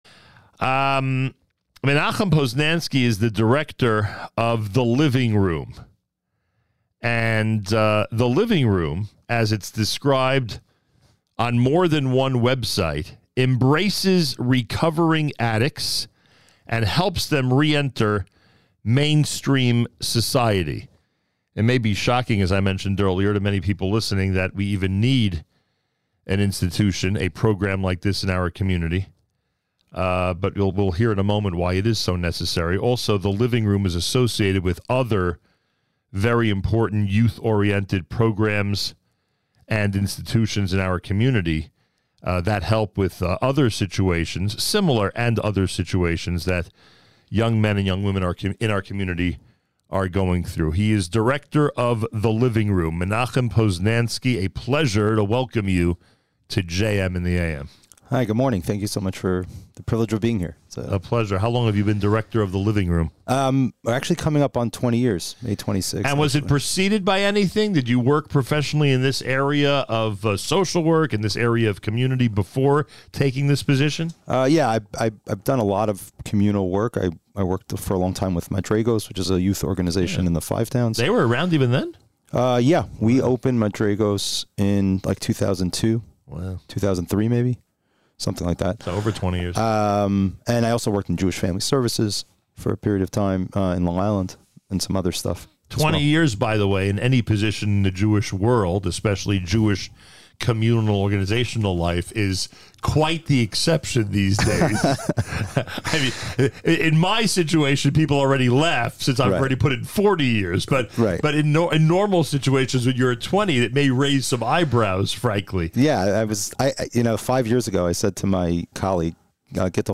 live in the studio during this morning’s JM in the AM to discuss their vital work for the Jewish community.